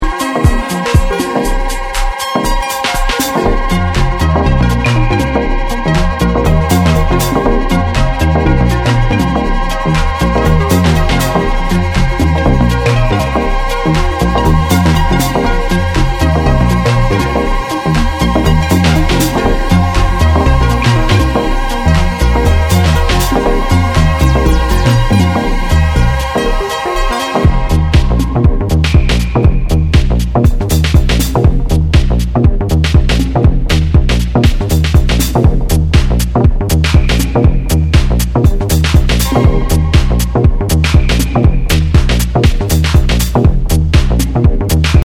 TECHNO/ELECTRO